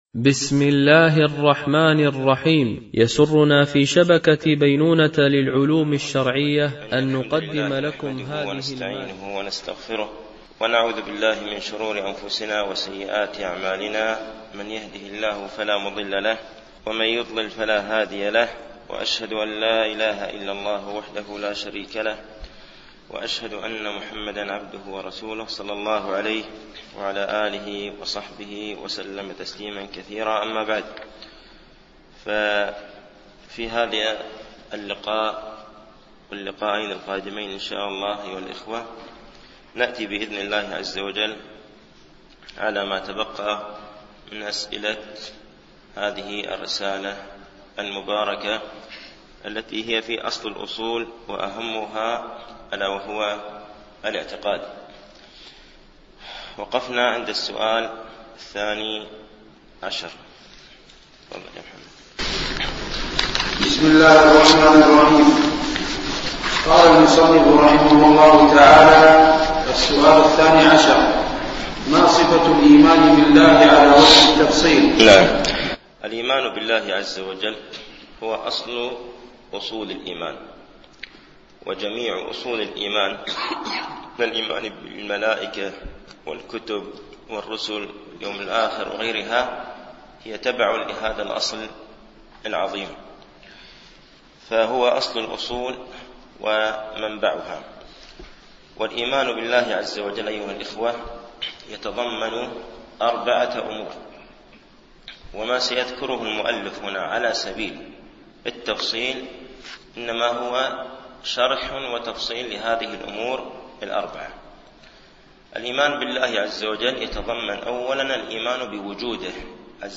MP3 Mono 22kHz 32Kbps (CBR)